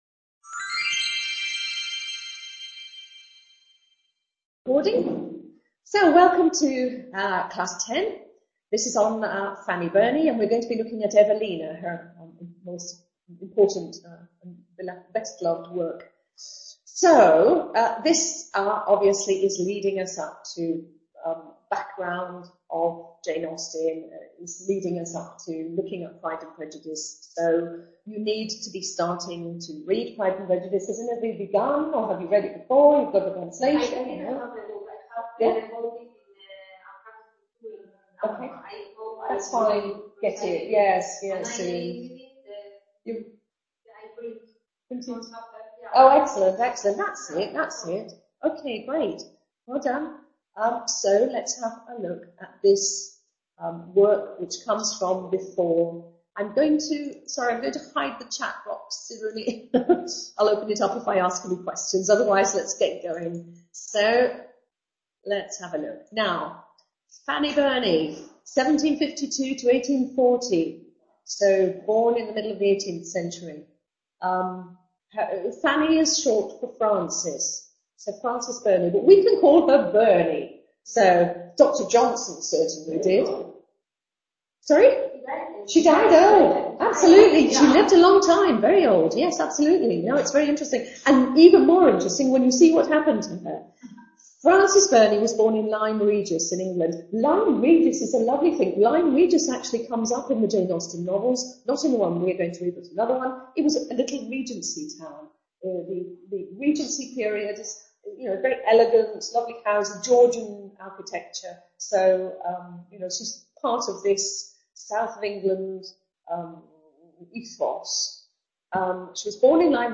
Lit II, Class 10